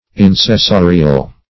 Search Result for " insessorial" : The Collaborative International Dictionary of English v.0.48: Insessorial \In`ses*so"ri*al\, a. (Zool.) 1.